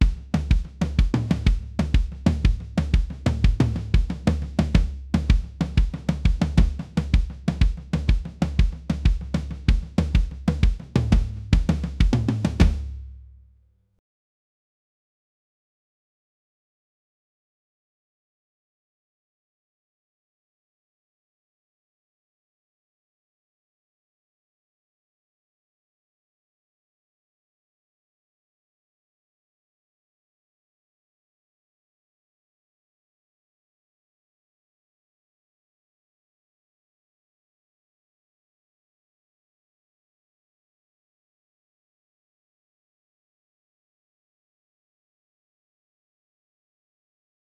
A model that transforms rhythmic audio inputs into professional drum performances by applying a multi-stage generative process, producing four unique variations per input.
• Converts diverse rhythmic sources (drums, beatboxing, body percussion) into studio-quality drum tracks